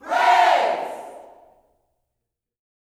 PRAISE  04.wav